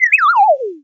fall1.wav